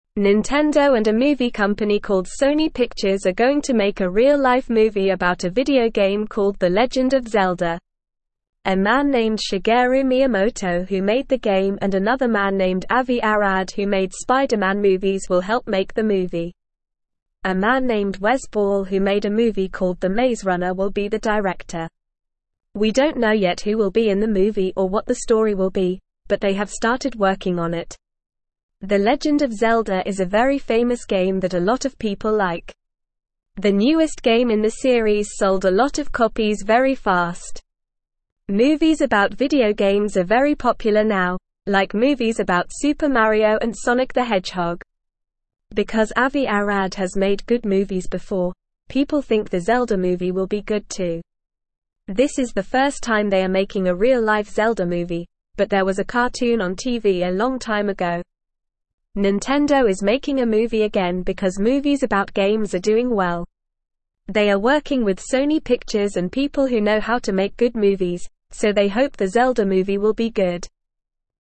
Normal
English-Newsroom-Lower-Intermediate-NORMAL-Reading-Nintendo-and-Sony-Making-The-Legend-of-Zelda-Movie.mp3